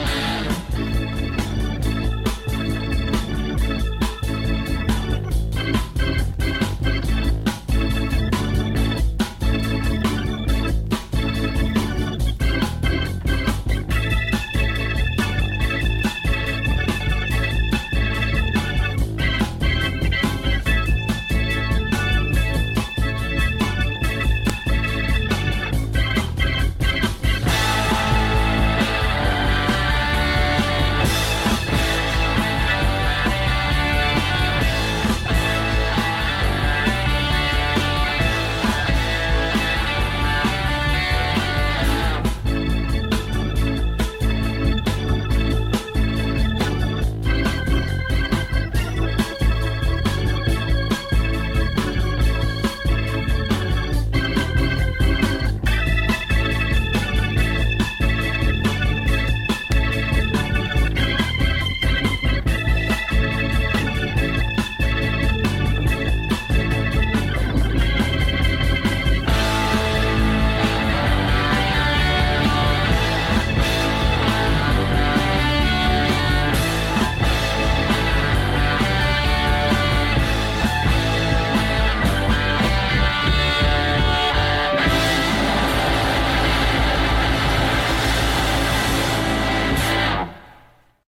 Avec par exemple les accords A (La) et D (Ré)
playback orgue-basse-batterie de la même méthode.